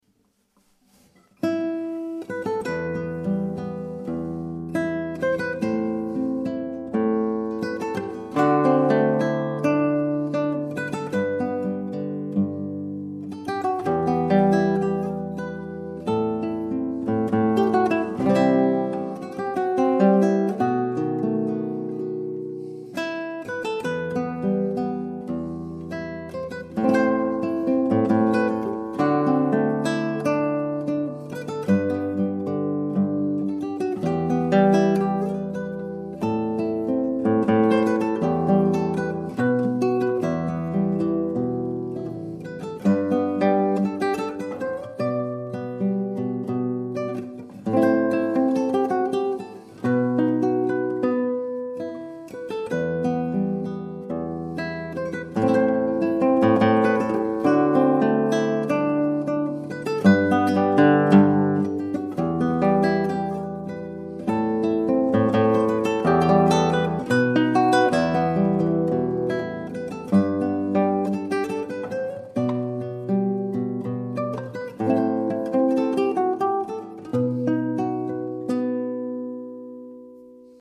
solo gitarre